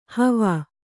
♪ havā